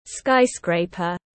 Skyscraper /ˈskaɪˌskreɪ.pər/